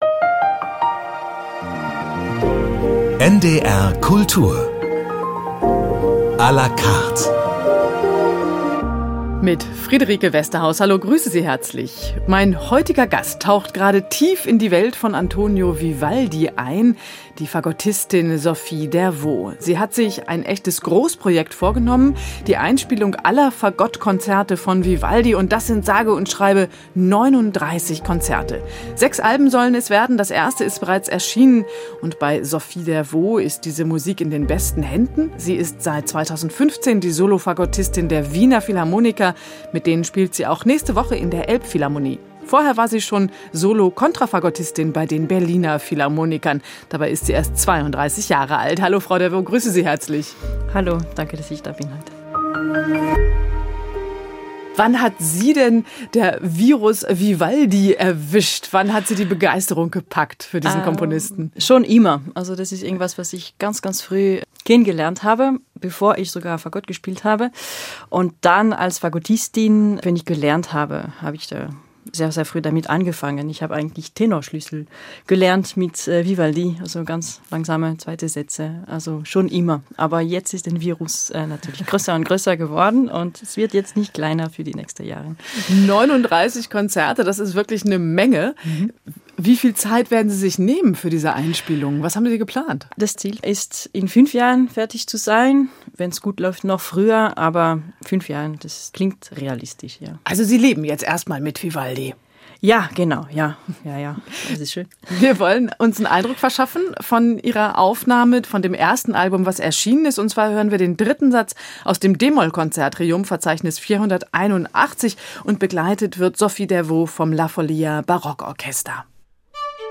Kinderhörspiel: Das Wunder von Björn - 16.06.2024